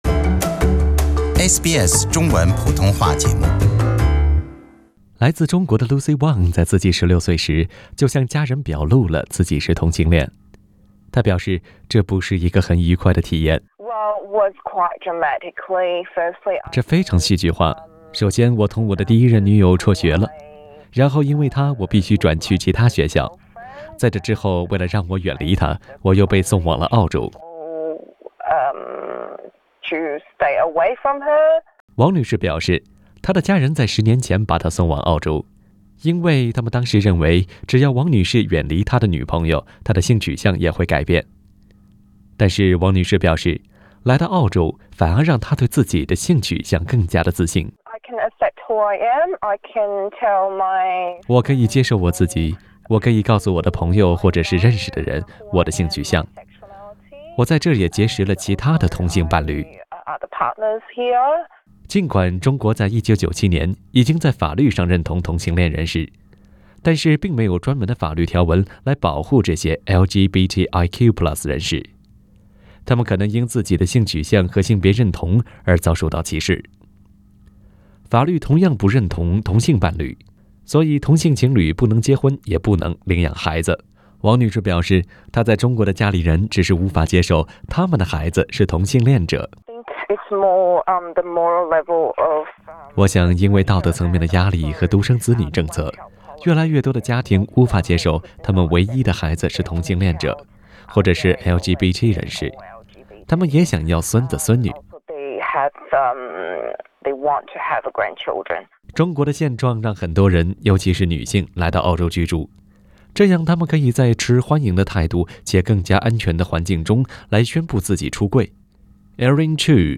请听本台记者为您带来的详细报道。